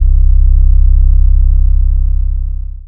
kits/TM88/808s/LASub_YC.wav at 32ed3054e8f0d31248a29e788f53465e3ccbe498
LASub_YC.wav